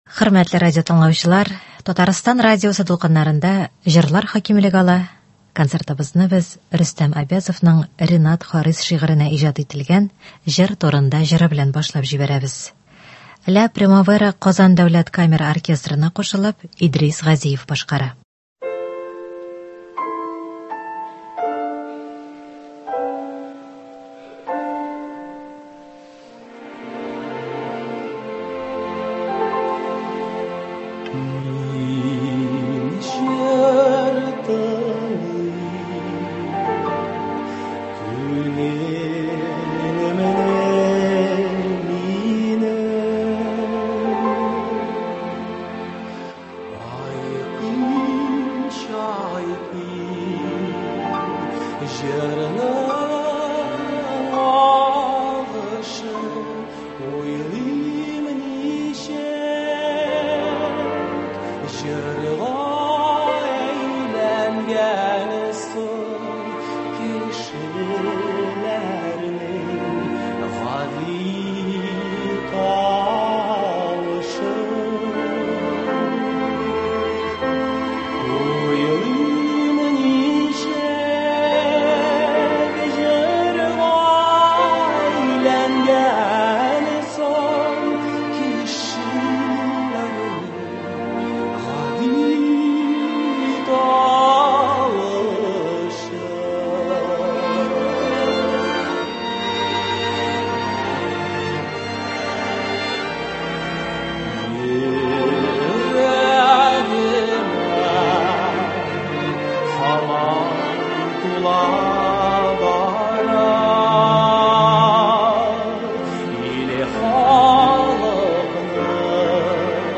Көндезге концерт.
Мөнәҗәтләр.